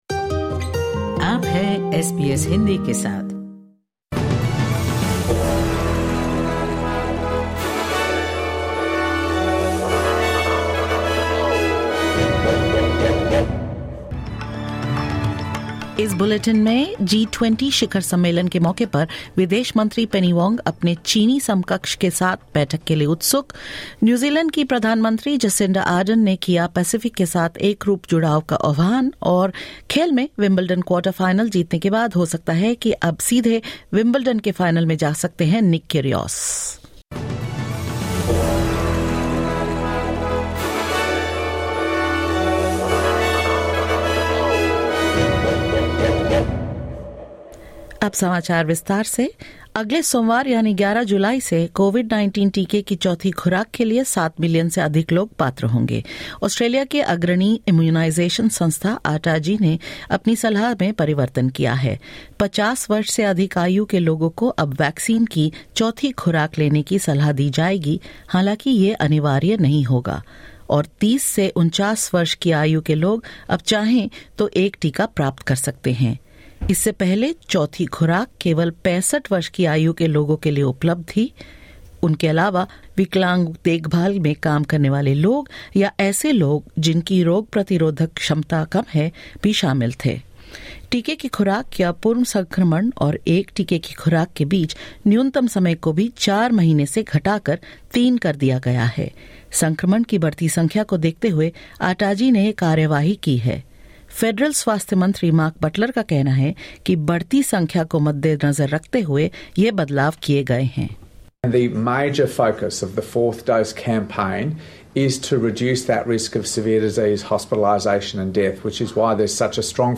SBS Hindi News 07 July 2022: Over seven million more people eligible for fourth COVID-19 vaccine